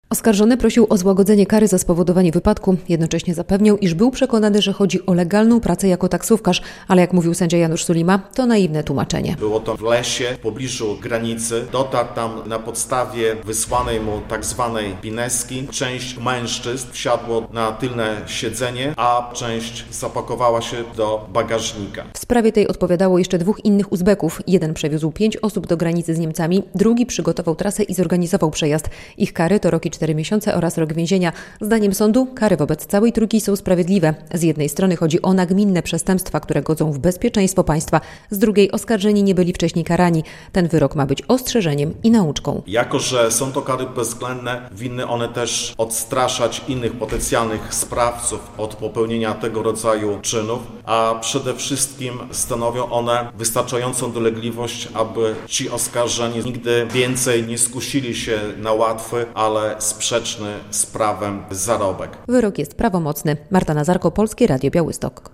Wyrok na kurierów - relacja